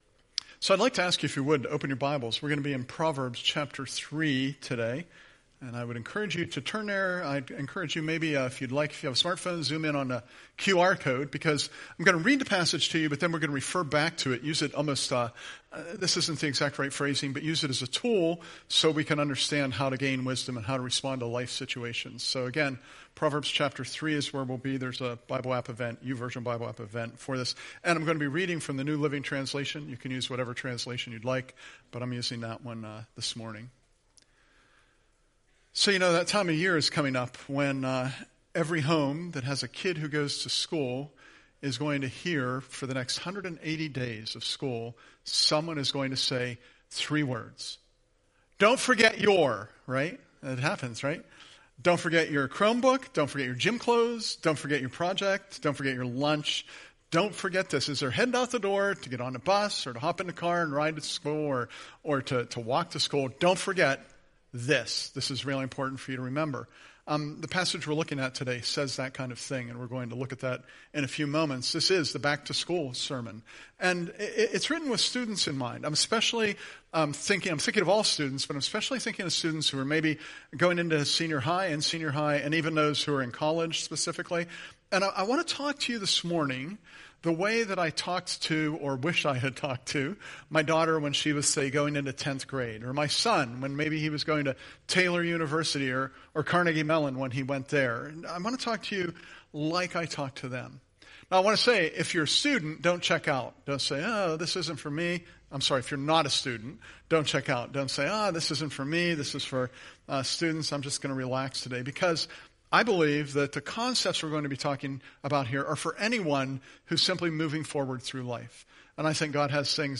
Back To School – Remember My Counsel – Curwensville Alliance Church Podcasts